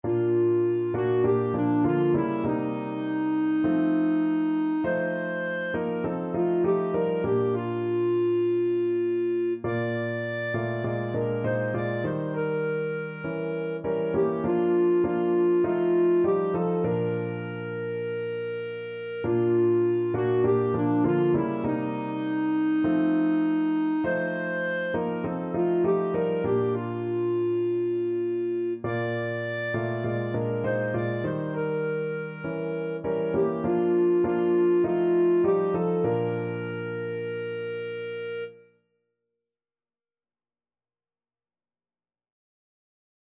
4/4 (View more 4/4 Music)
Traditional (View more Traditional Clarinet Music)